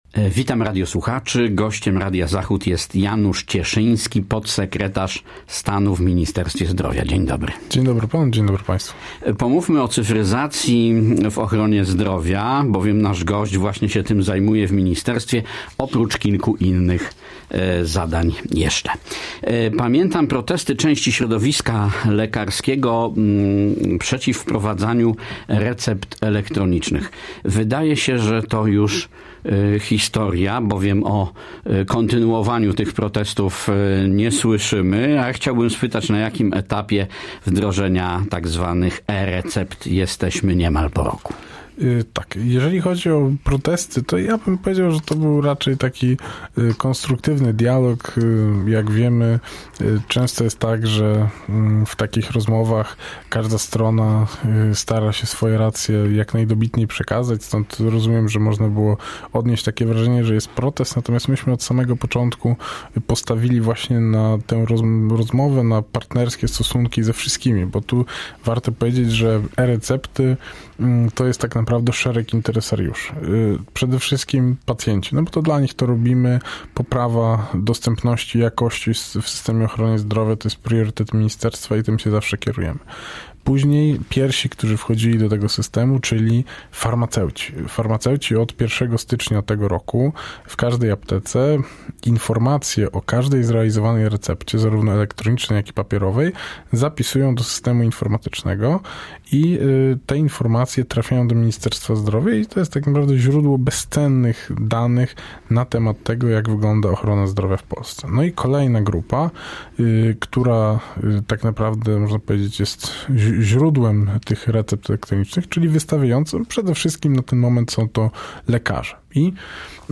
Z wiceministrem zdrowia rozmawia